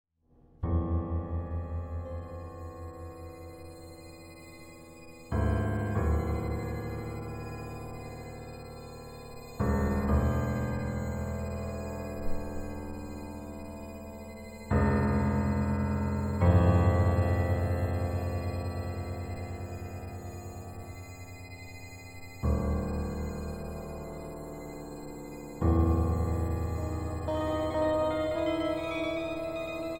Free improvisation that stretches the limits